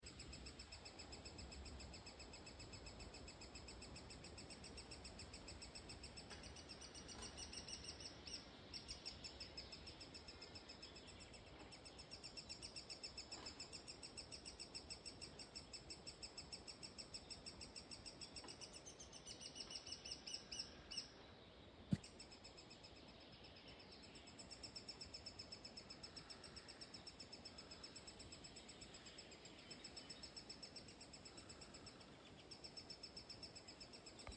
поползень, Sitta europaea
Administratīvā teritorijaValkas novads
СтатусВзволнованное поведение или крики